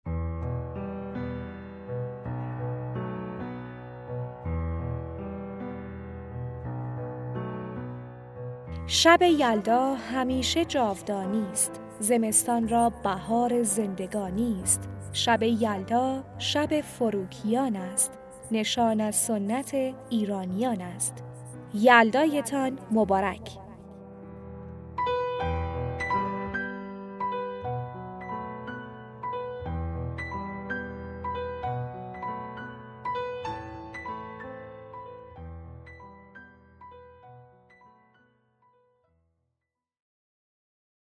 کارت پستال صوتی تبریک یلدا رسمی